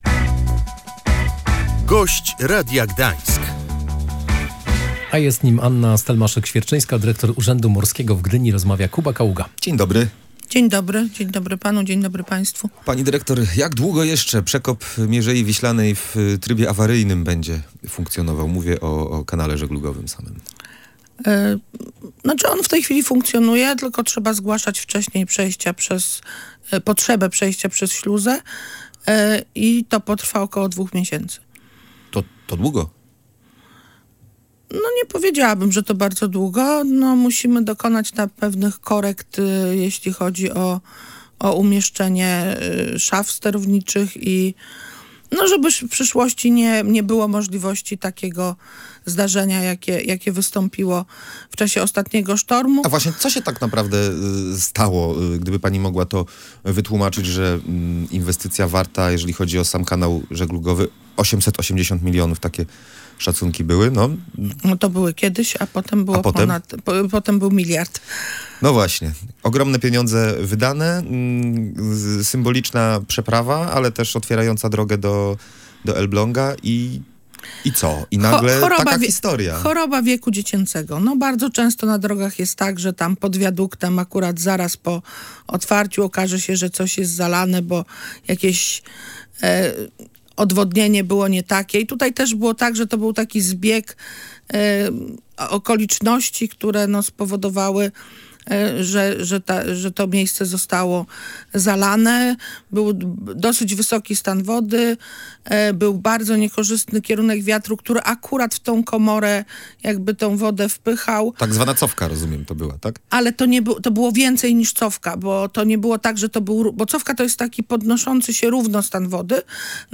Jak mówiła na antenie Radia Gdańsk Anna Stelmaszyk-Świerczyńska, dyrektor Urzędu Morskiego w Gdyni, ruch został przywrócony, ale nadal są utrudnienia w żegludze.
Gość Radia Gdańsk